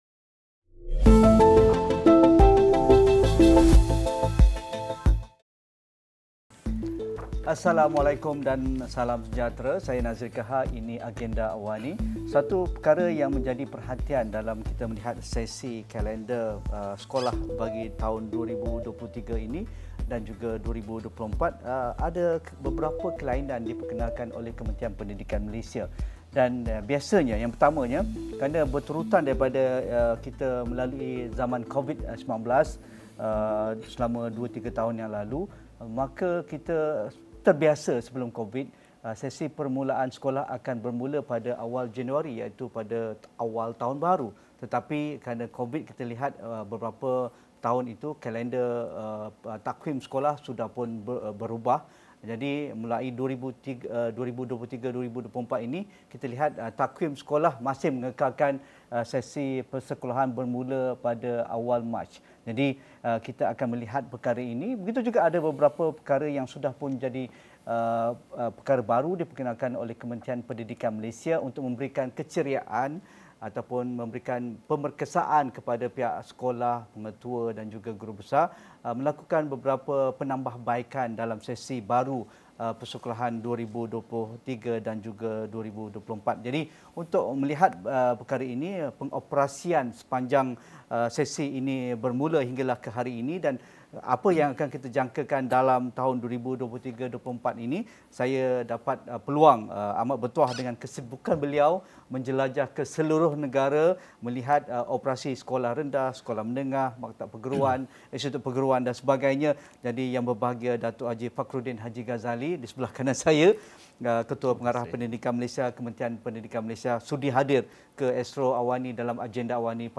Kementerian Pendidikan Malaysia (KPM) beri fokus kepada ikhtiar melahirkan Anak yang Baik lagi Cerdik (ABC) bertepatan dengan visi KPM untuk beri pendidikan yang berkualiti bagi melahirkan insan terdidik dan dapat membentuk negara Madani. Diskusi 9 malam